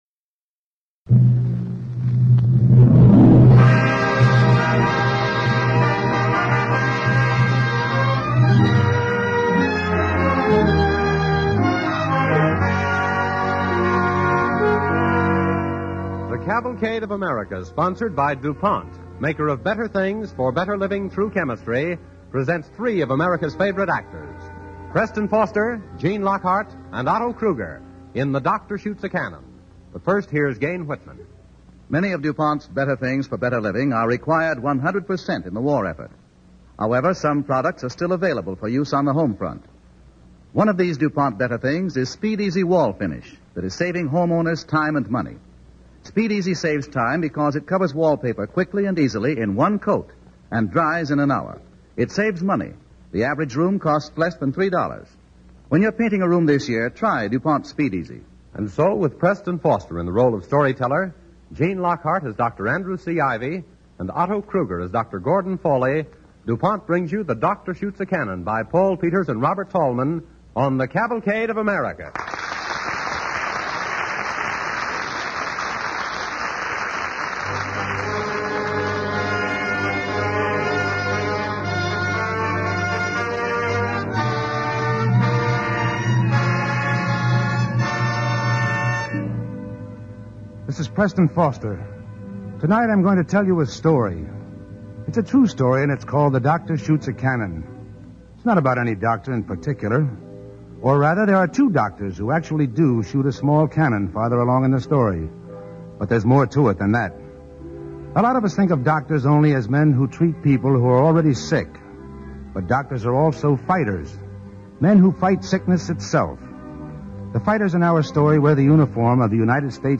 The Doctor Shoots a Cannon, starring Preston Foster, Gene Lockhart and Otto Kruger
Cavalcade of America Radio Program